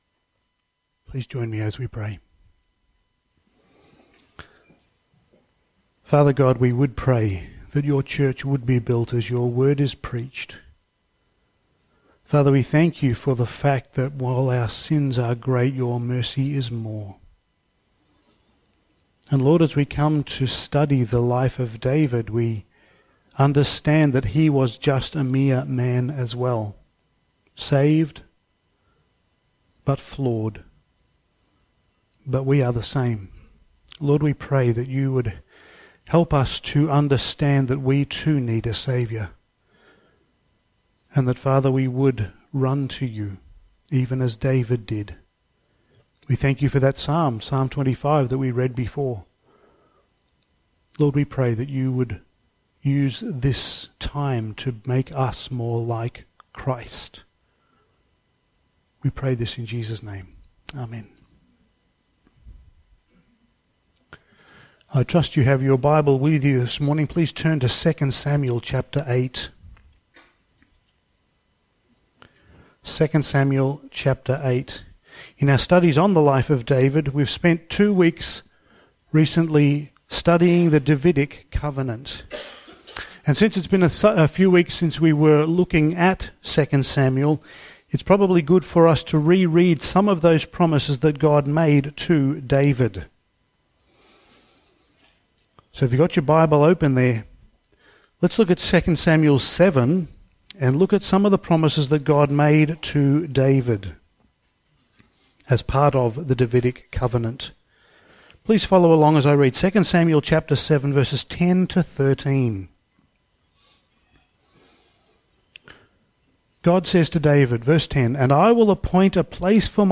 Passage: 2 Samuel 8:1-18 Service Type: Sunday Morning